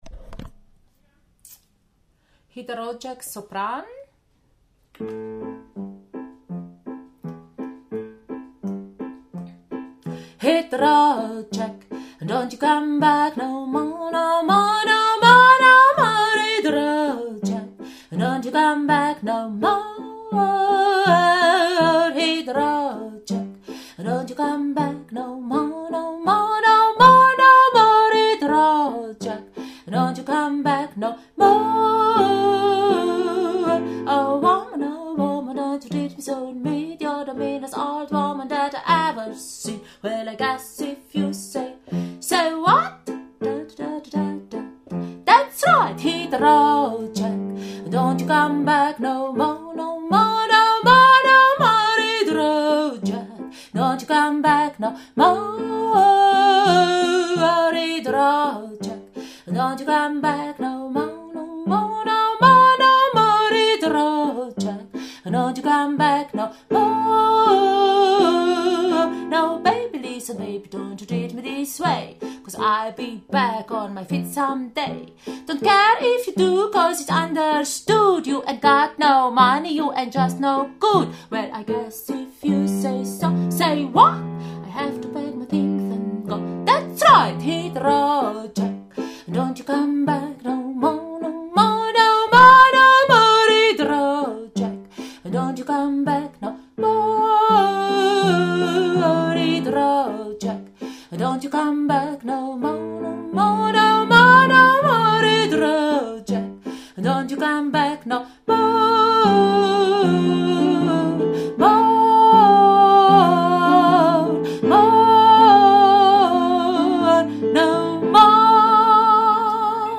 Hit the road Jack – Sopran